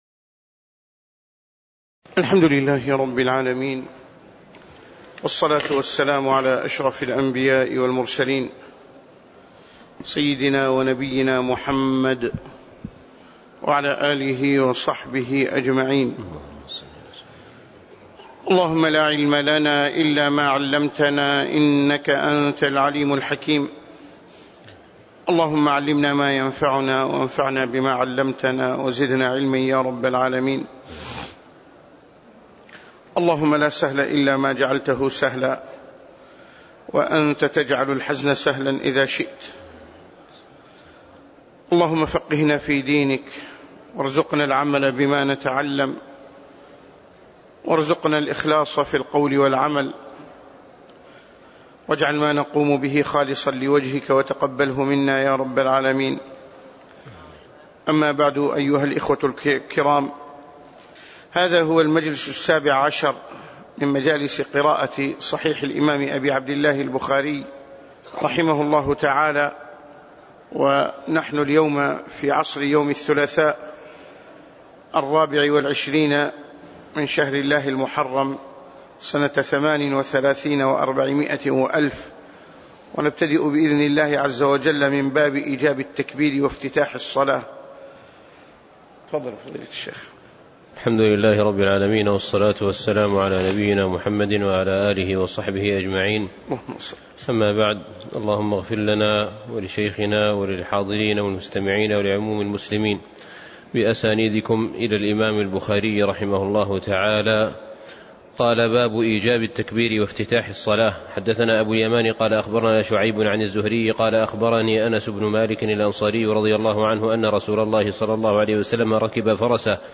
تاريخ النشر ٢٤ محرم ١٤٣٨ هـ المكان: المسجد النبوي الشيخ